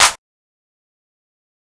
Clap 7.wav